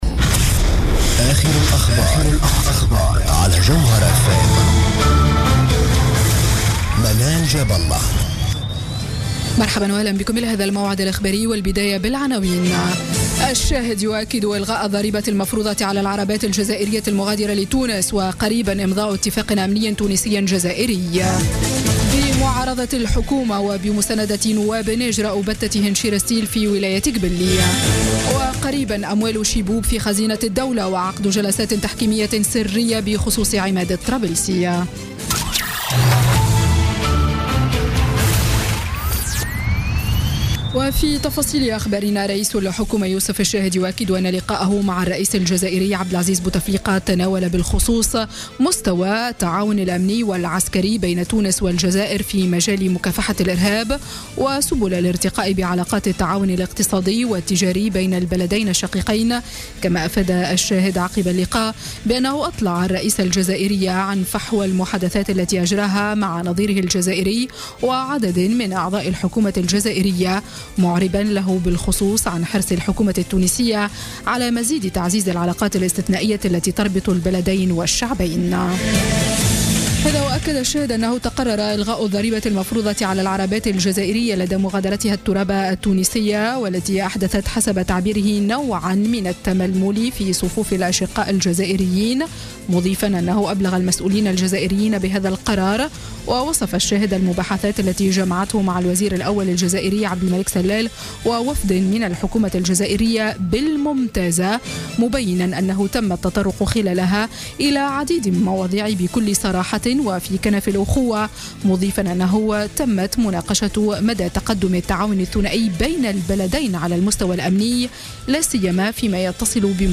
نشرة أخبار السابعة مساء ليوم الأحد 9 أكتوبر 2016